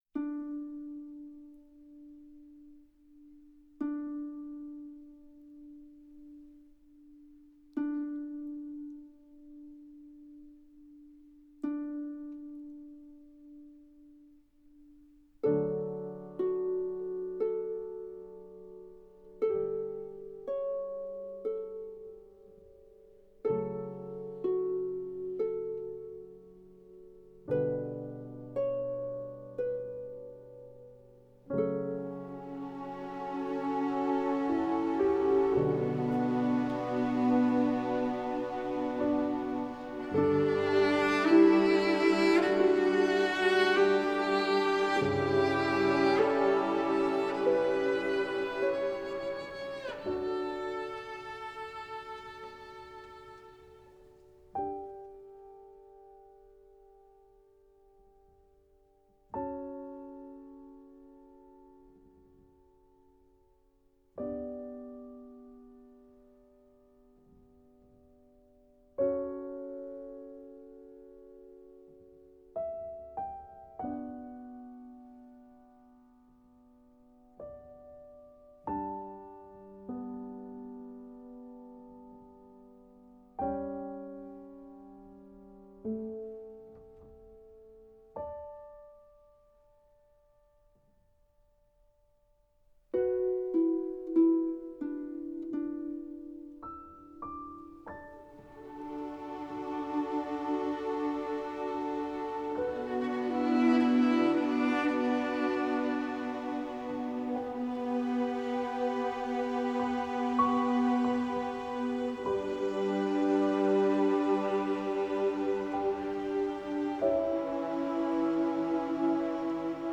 آهنگ آروم و فوق العاده در ضمن بی کلام